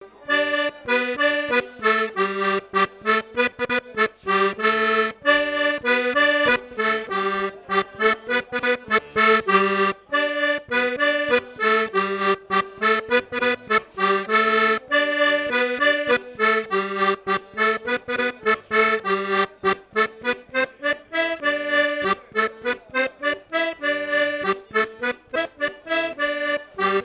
danse : bal de Jugon
Fête de l'accordéon
Pièce musicale inédite